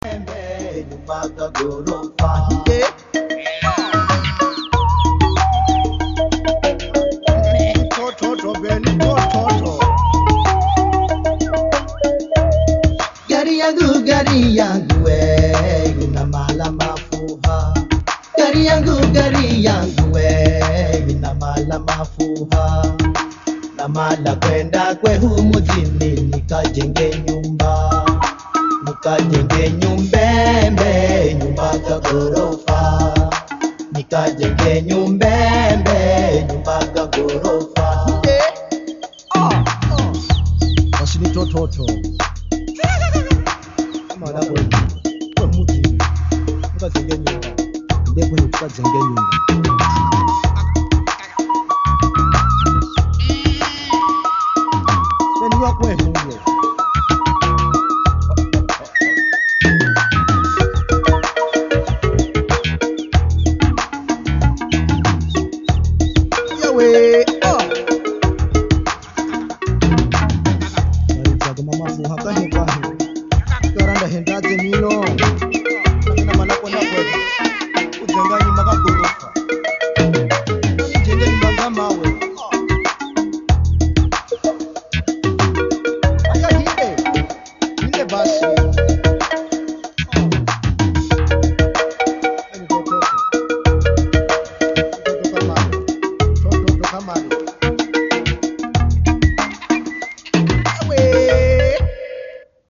Wer kennt sich in afrikanischer Musik aus?
Ich habe da ein Stück vor vielen Jahren aus dem nächtlichen Radio aufgenommen, welches mich immer noch fasziniert: